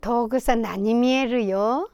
Aizu Dialect Database
Final intonation: Rising
WhP Intonation: Rising
Location: Aizumisatomachi/会津美里町
Sex: Female